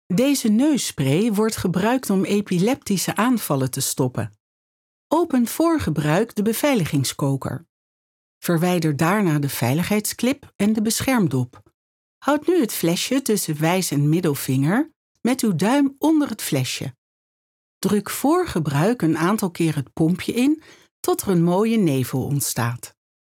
Travieso, Versátil, Seguro, Amable, Cálida
Corporativo